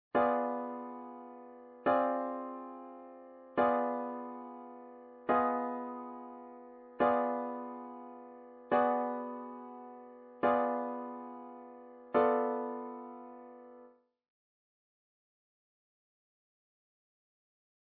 diminished 7th arpeggio